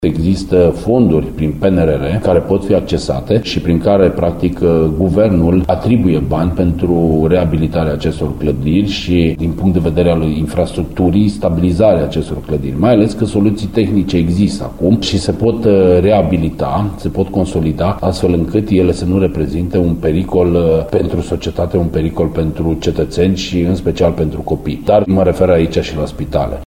Expertiza clădirilor publice, cu risc ridicat la cutremure, din Timișoara, ar putea fi făcută cu bani din Planul Național de Redresare și Reziliență, spune viceprimarul Cosmin Tabără.